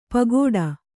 ♪ pagōḍa